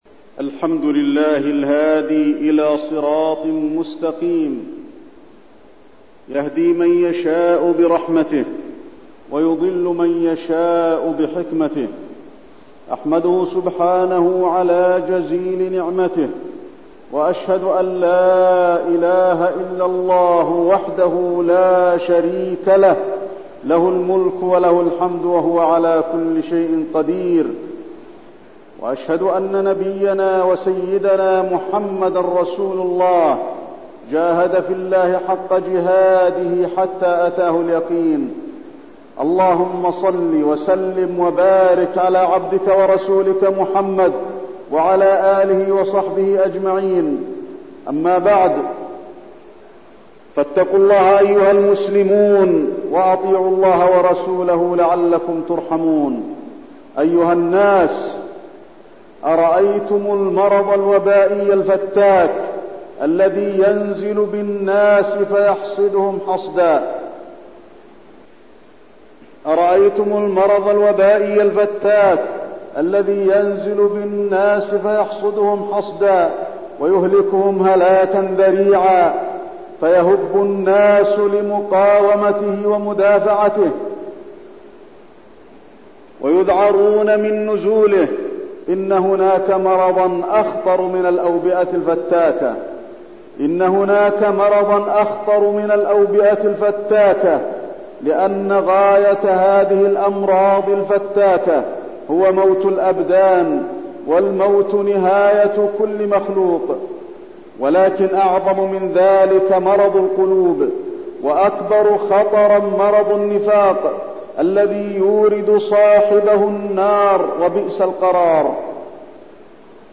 تاريخ النشر ٢١ محرم ١٤٠٩ هـ المكان: المسجد النبوي الشيخ: فضيلة الشيخ د. علي بن عبدالرحمن الحذيفي فضيلة الشيخ د. علي بن عبدالرحمن الحذيفي النفاق